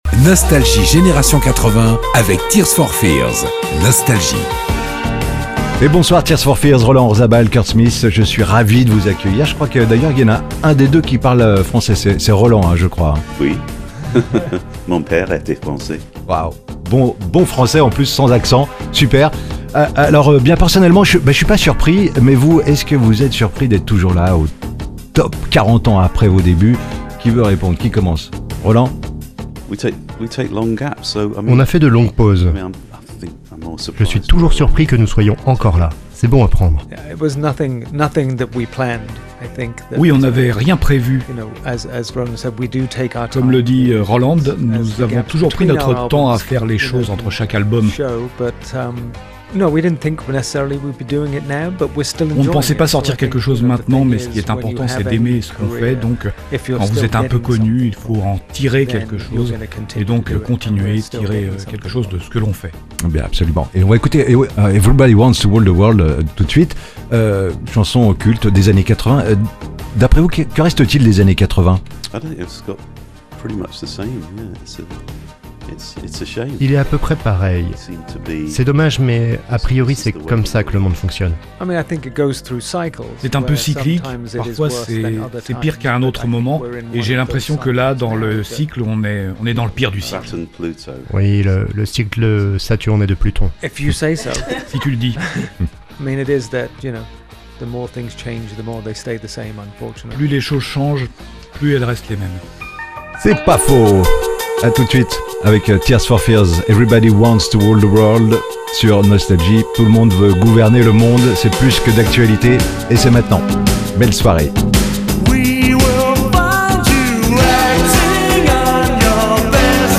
Les plus grands artistes sont en interview sur Nostalgie.